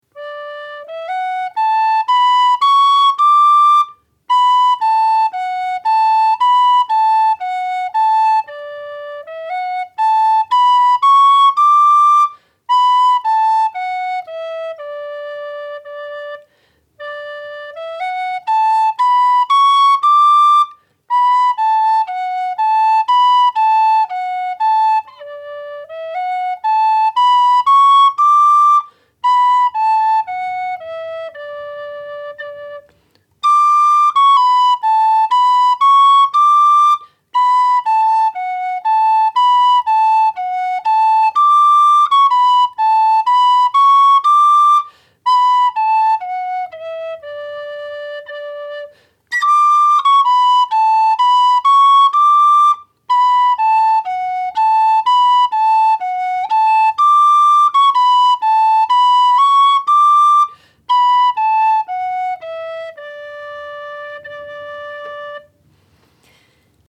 Absolute Beginner Tin Whistle Lessons
D Major
Polka